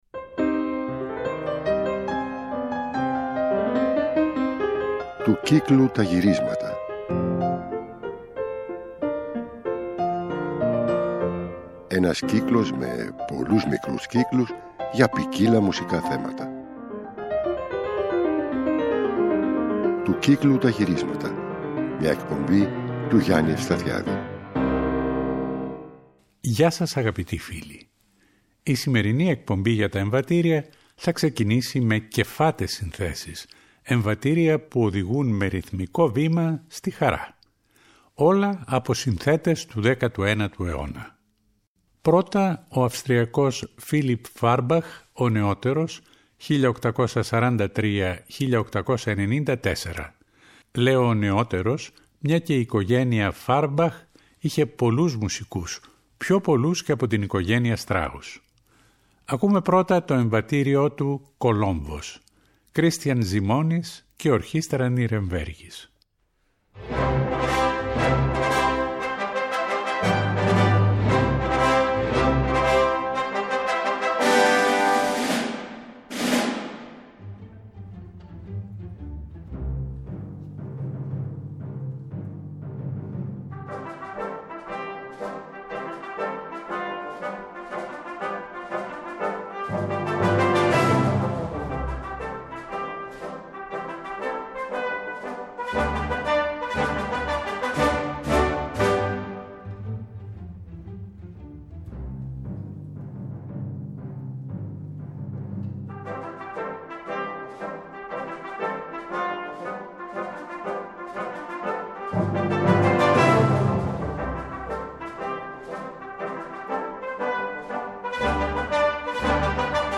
Στο α’ μέρος κεφάτα, χαρούμενα εμβατήρια από συνθέτες του 19ου αιώνα που έχουν ασχοληθεί με οπερέτα, βαλσάκια και πόλκες (Fahrbach, Bilse, Gungl, Szechenyi).
Στο β’ μέρος το κλίμα αλλάζει με εμβατήρια πένθιμα (σονάτες του Liszt και του Beethoven, ορατόριο του Haendel, πένθιμη βασιλική ακολουθία του Purcell). Στην έξοδο ένα θλιμμένο εμβατήριο των αφροαμερικάνων των ΗΠΑ.